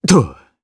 voices / heroes / jp
Kibera-Vox_Landing_jp.wav